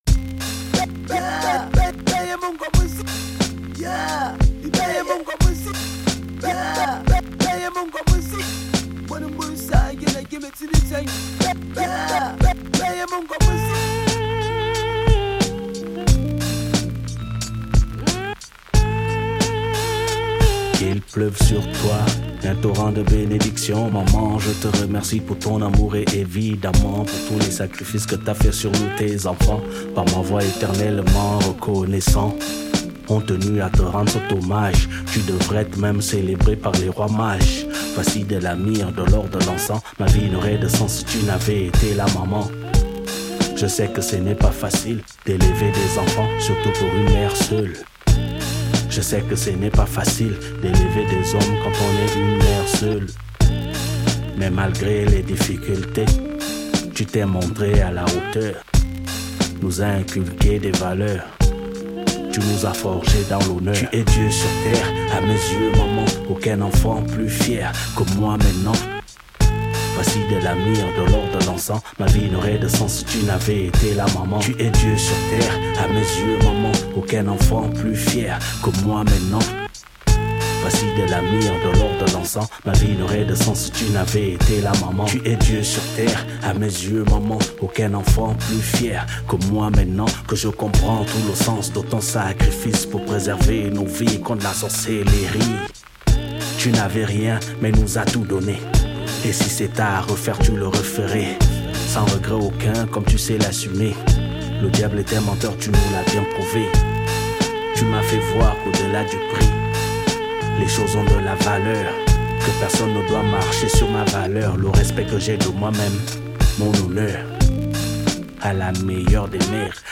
Genre : HipHop